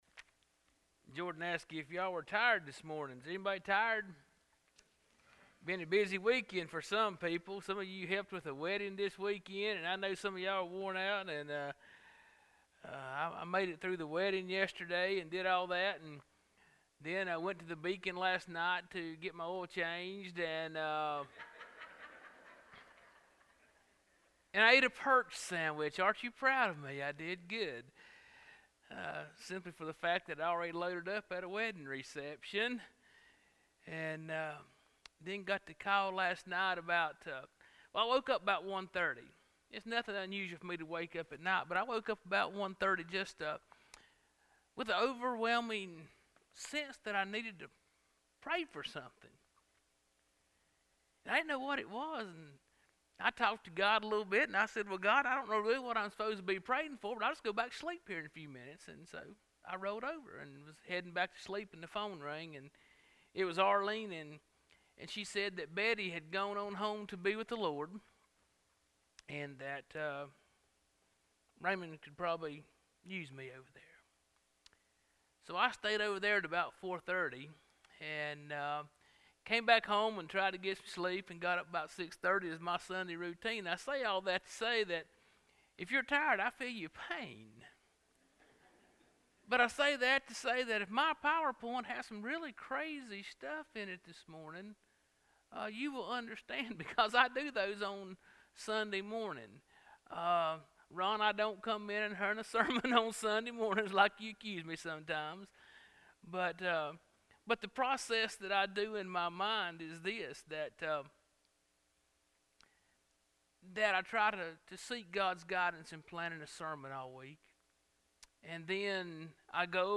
Spencer Baptist Church Sermons